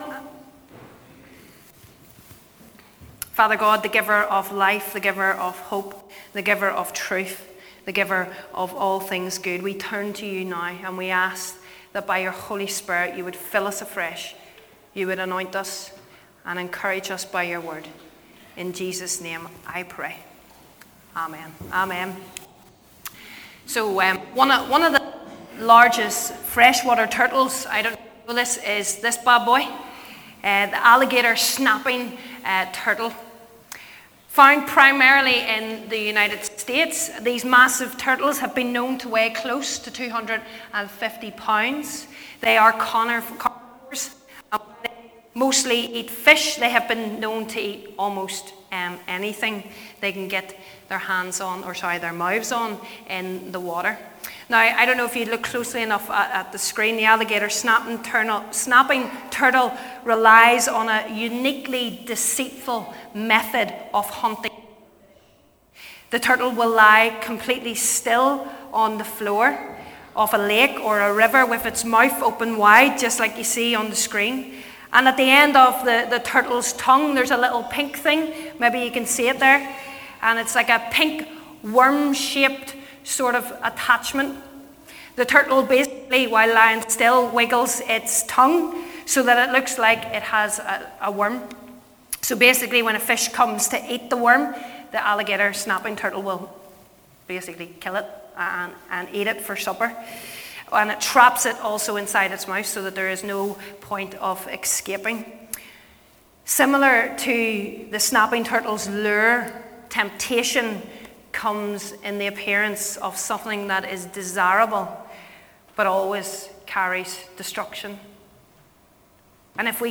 Sunday 15th March 2020 | St Mark's Portadown
15th-March_Sermon.mp3